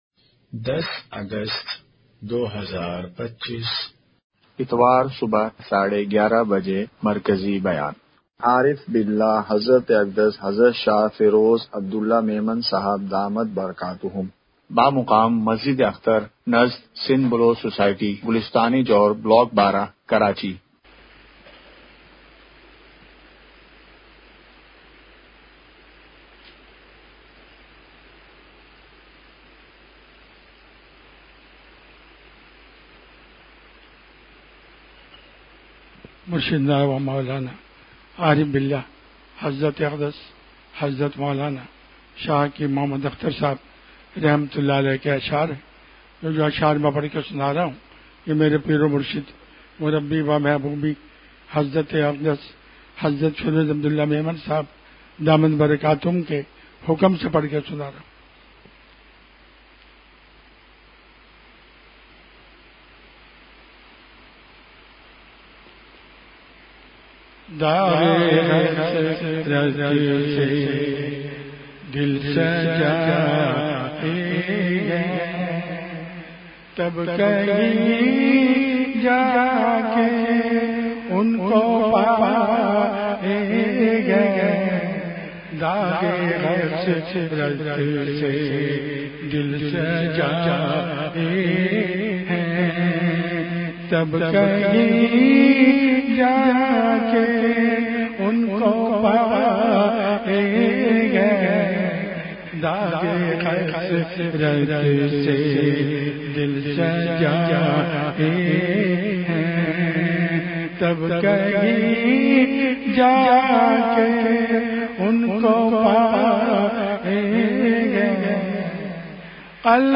Please download the file: audio/mpeg مجلس محفوظ کیجئے اصلاحی مجلس کی جھلکیاں مقام:مسجد اخترگلستان جوہر کراچی